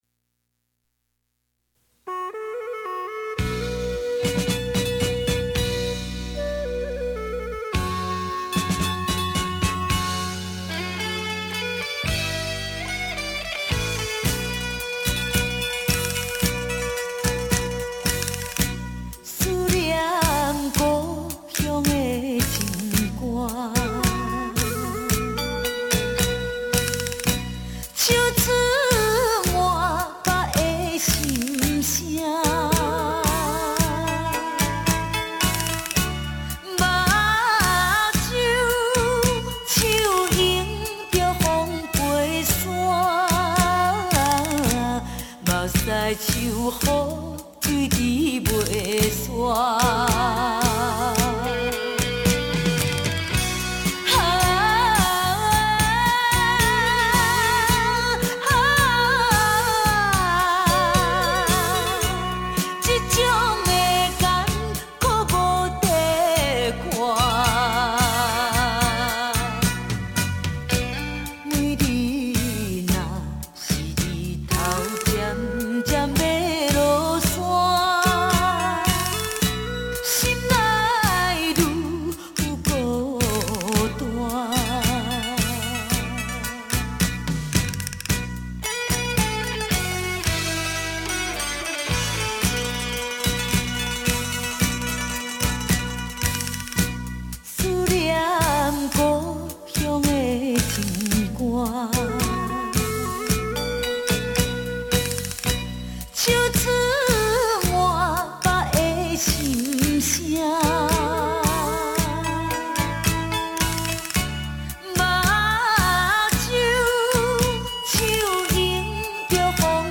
旋律简单，很容易让人朗朗上口。